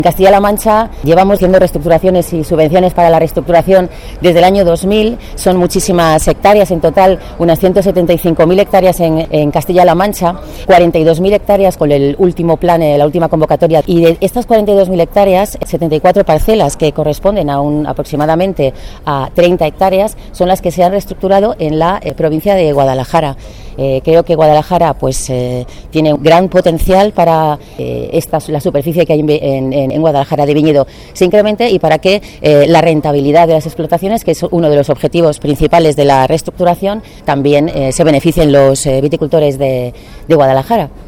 La directora general de Agricultura y Ganadería, Cruz Ponce, habla de las ayudas a la reestructuración del viñedo.